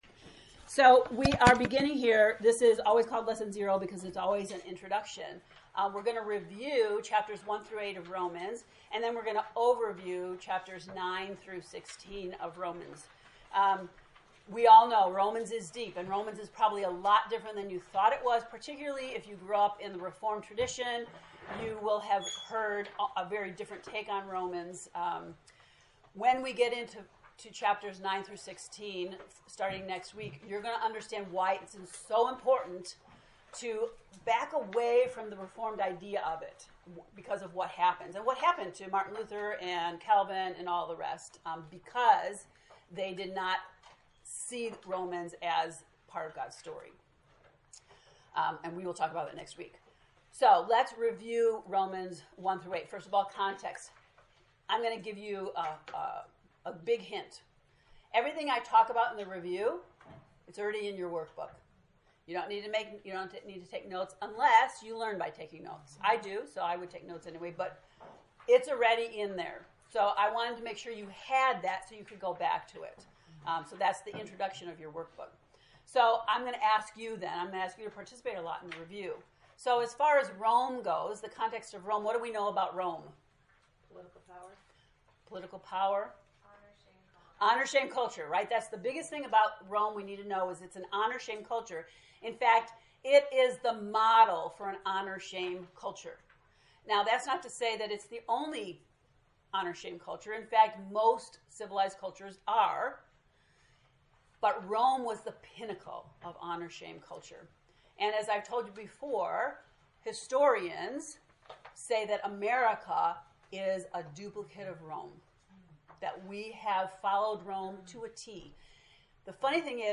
To listen to the lesson 0 lecture, “Review & Overview,” click below: